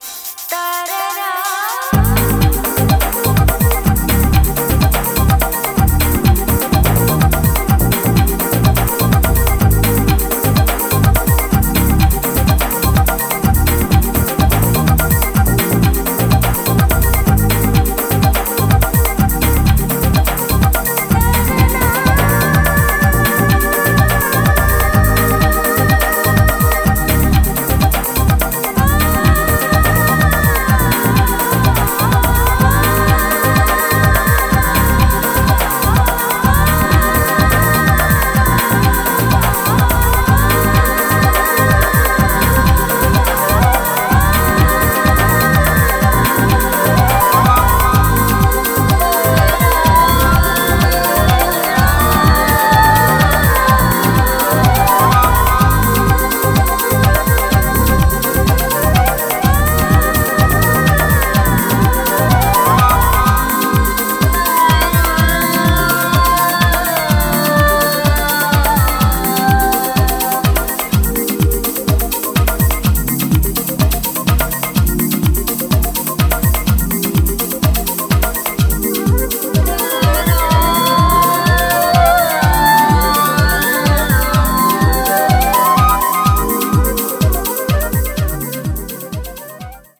ジャンル(スタイル) AMBIENT / TECHNO / HOUSE